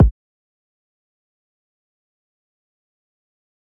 SizzKick2.wav